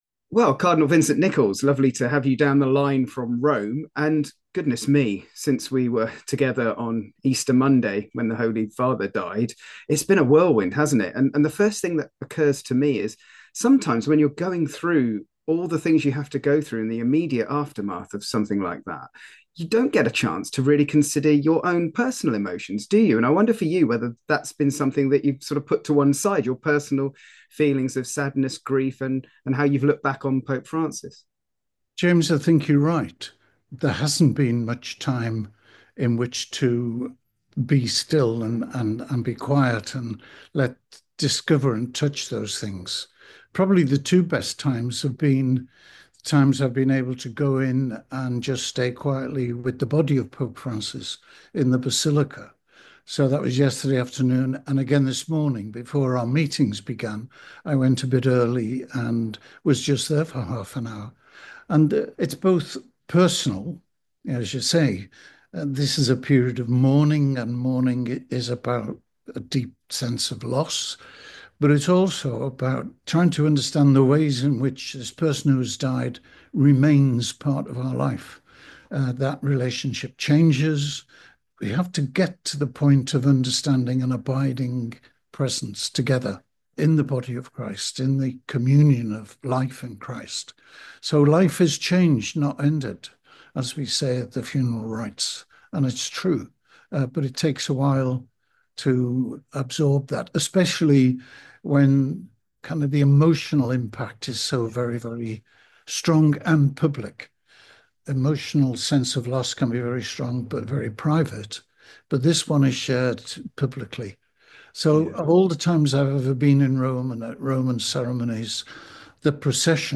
Three days after the death of Pope Francis, Cardinal Vincent Nichols speaks to us from Rome about his personal feelings and experiences ahead of the papal funeral.